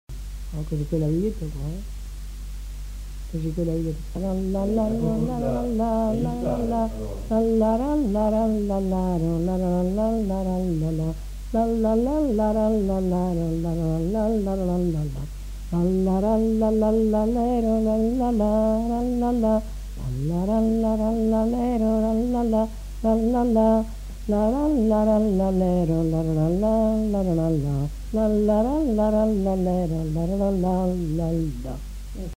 Aire culturelle : Haut-Agenais
Lieu : Cancon
Genre : chant
Effectif : 1
Type de voix : voix de femme
Production du son : fredonné
Danse : youska